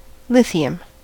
lithium: Wikimedia Commons US English Pronunciations
En-us-lithium.WAV